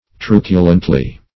Truculently \Tru"cu*lent*ly\, adv.